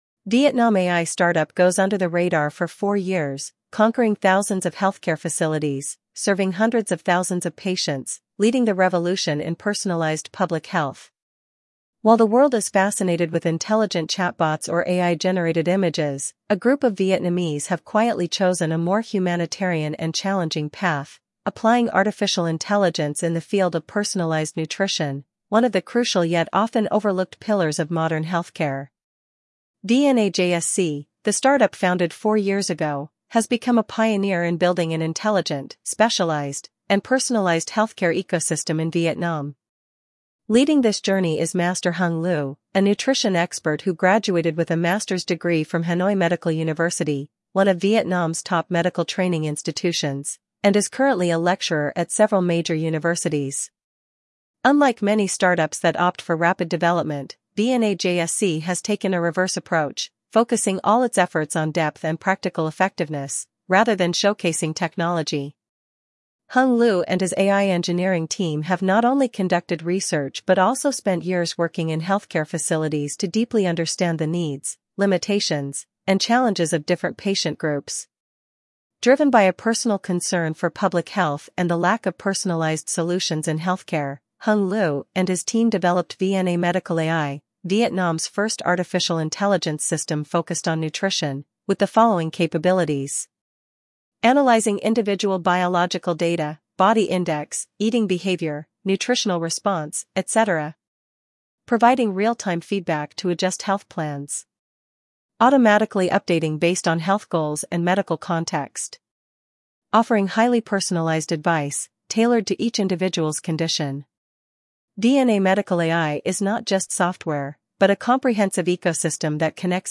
giong-nu-EL.mp3